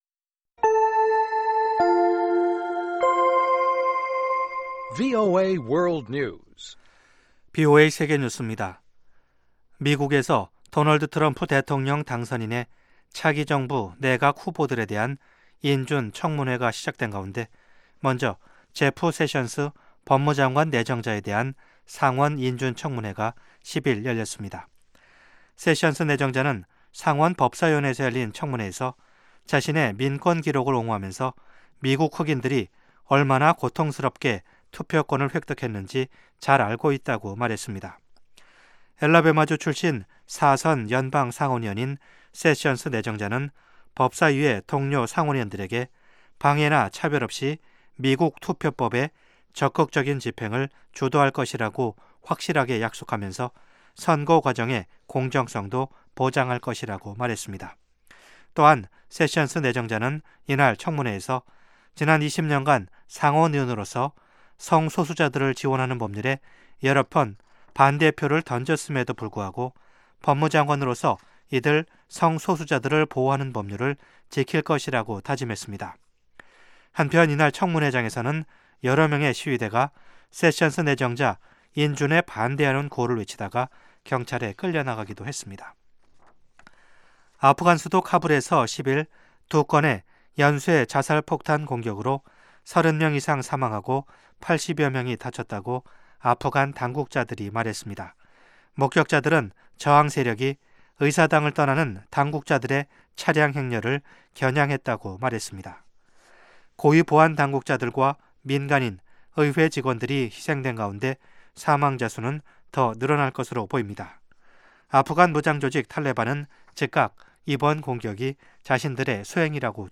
VOA 한국어 방송의 아침 뉴스 프로그램 입니다. 한반도 시간 매일 오전 5:00 부터 6:00 까지, 평양시 오전 4:30 부터 5:30 까지 방송됩니다.